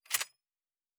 pgs/Assets/Audio/Sci-Fi Sounds/Weapons/Weapon 10 Foley 1.wav at 7452e70b8c5ad2f7daae623e1a952eb18c9caab4
Weapon 10 Foley 1.wav